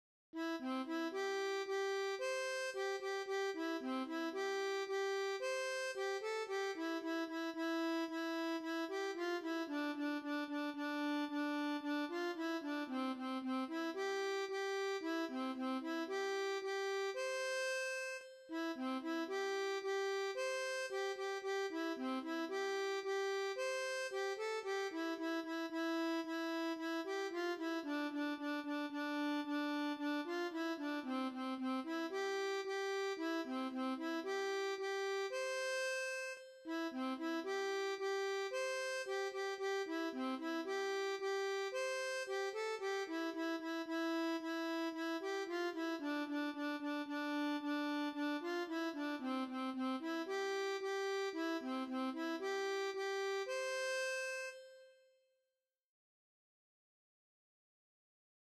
La m�lodie.
Envoyer un formulaire de recherche Ronde de Mai Compositeur : Anonyme Chant Traditionnel FAILED (the browser should render some flash content here, not this text).